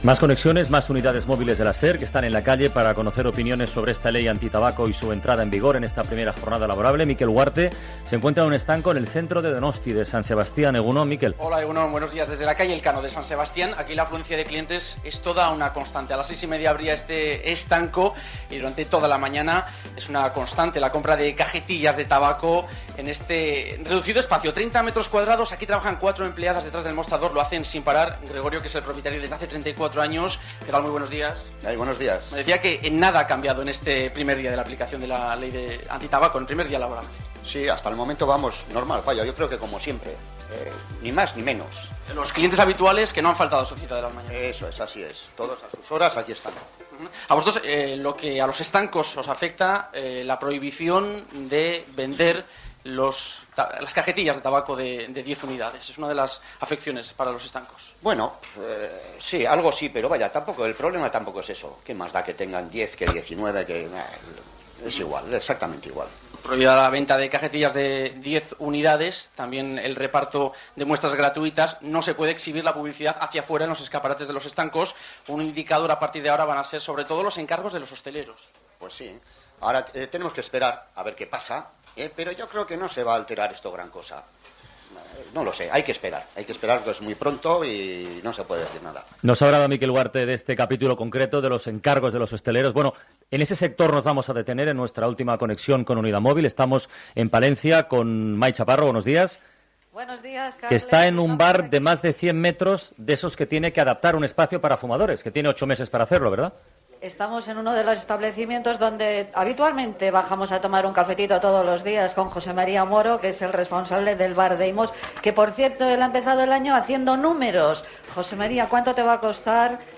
Connexió amb un estanc de Donosti i un bar de Palència per informar del primer dia de l'apliació de la nova Llei antitabac
Info-entreteniment